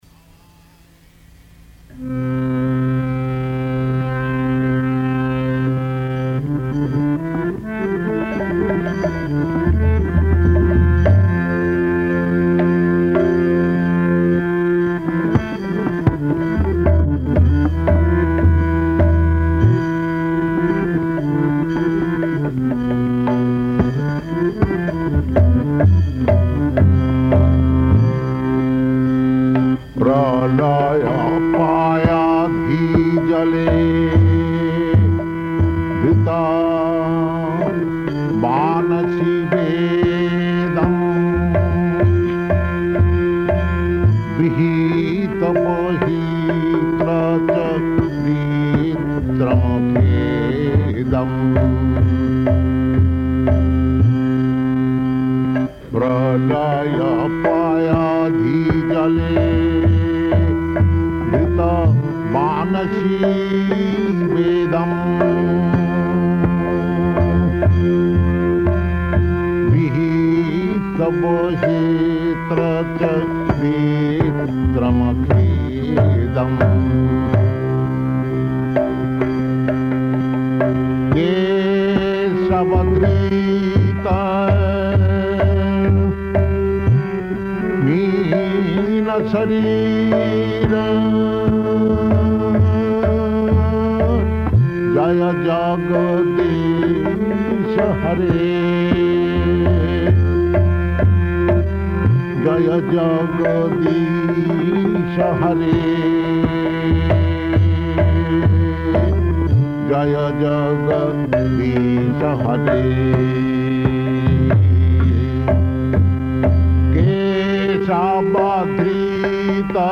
Location: Los Angeles
[Sings Dāsavatāra-stotra ]